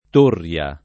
[ t 1 rr L a ]